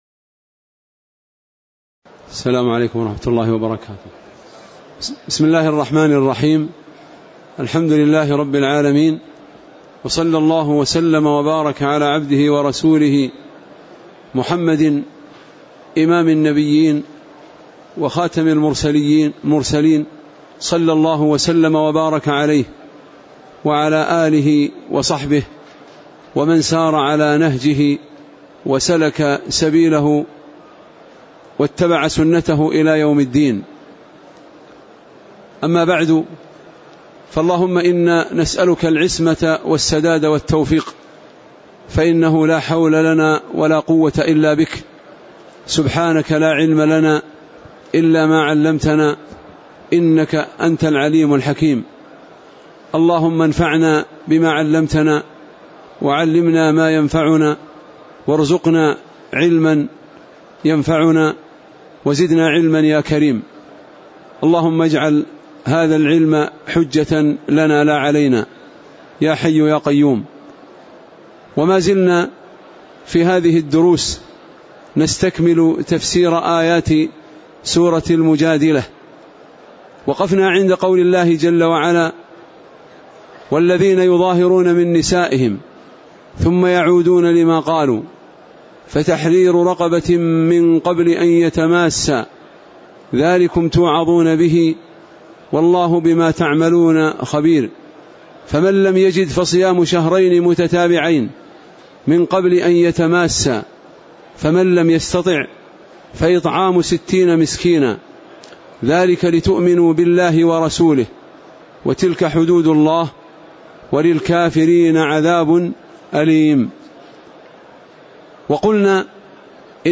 تاريخ النشر ١٦ صفر ١٤٣٨ هـ المكان: المسجد النبوي الشيخ